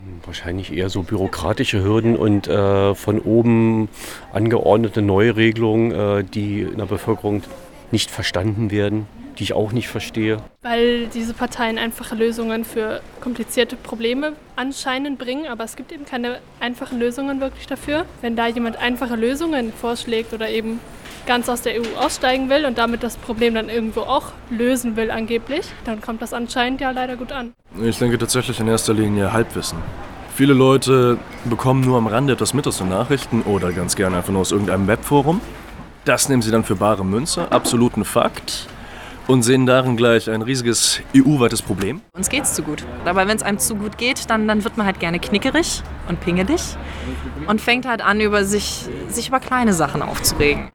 Euroskepsis – Umfrage